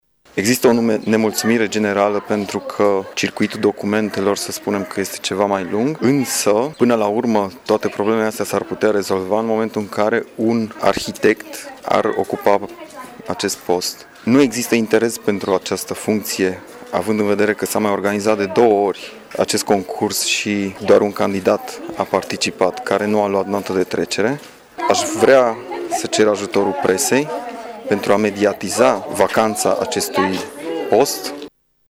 Semnalul de alarmă a fost tras în această dimineaţă de arhitectul-şef al judeţului Mureş, Răzvan Şipoş, care a susţinut o conferinţă de presă la Sighişoara.